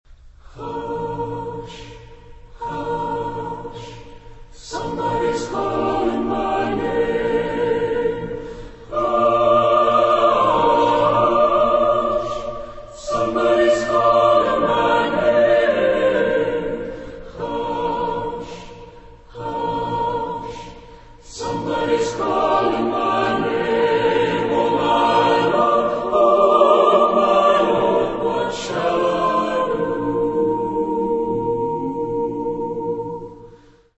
Caractère de la pièce : modéré
Type de choeur : SATB  (4 voix mixtes )
Tonalité : si bémol majeur